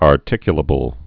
(är-tĭkyə-lə-bəl)